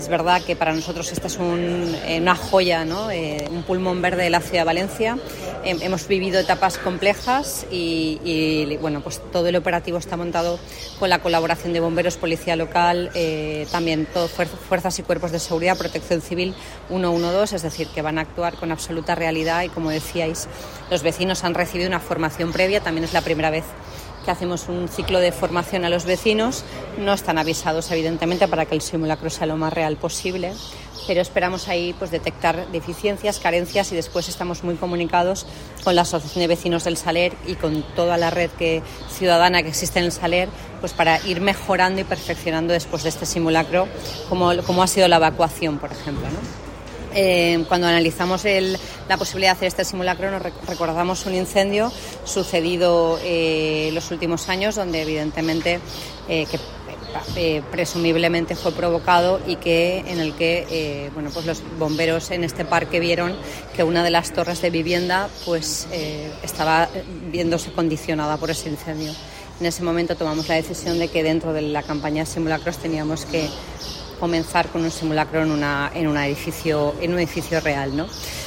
• Momento en el que se pone en marcha el dispositivo contra incendios